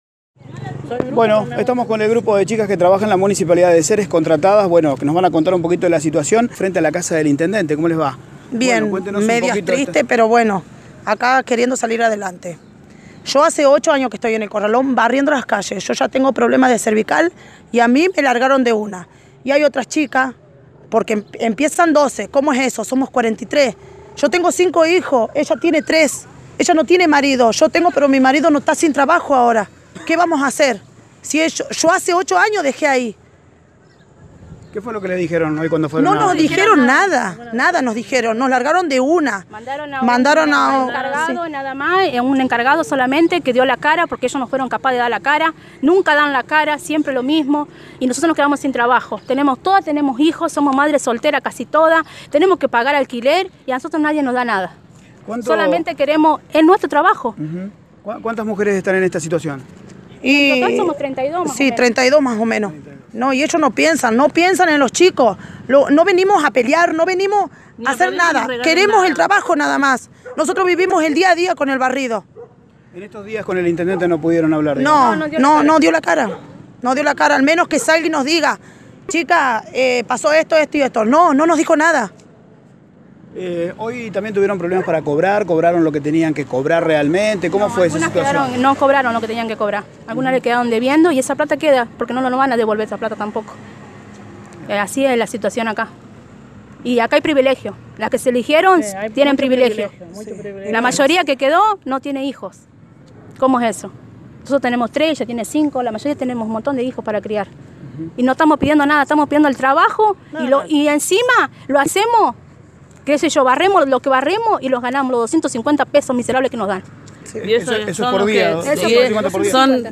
escuchar a las trabajadoras…..